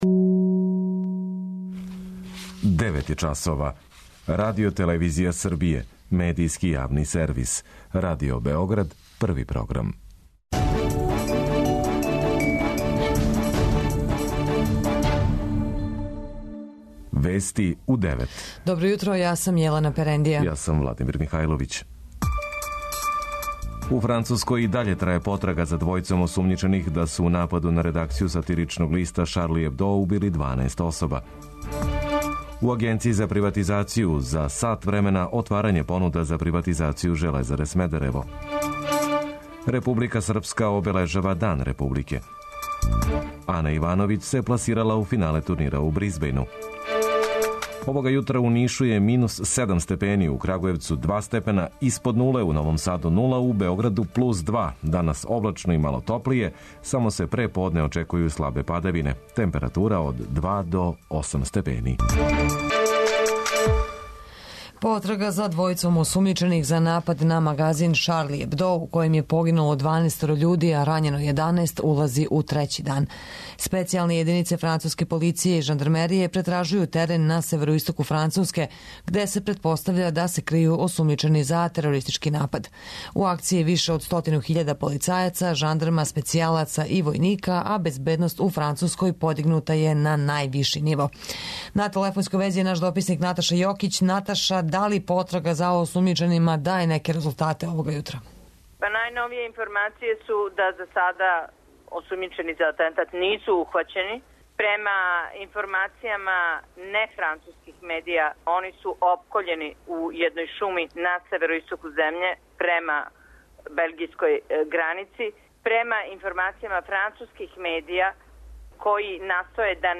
Вести уређују и воде